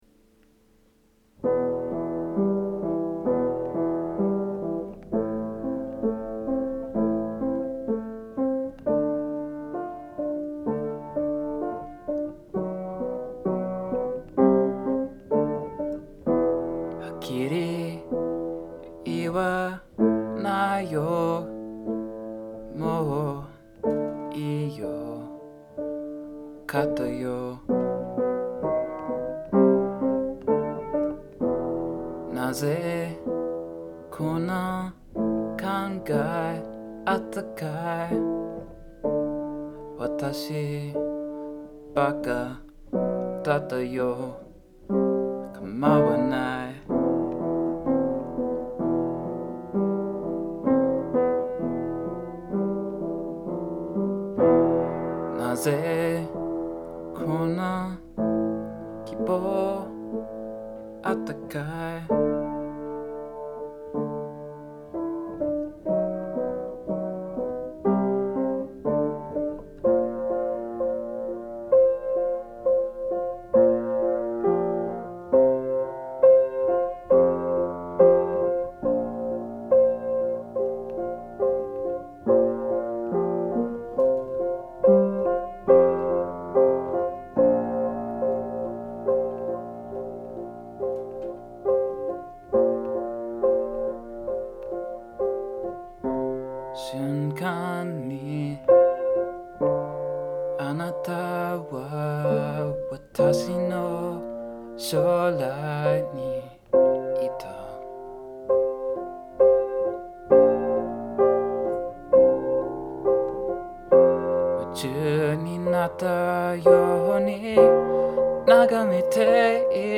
10 Nagameteiru (rough).mp3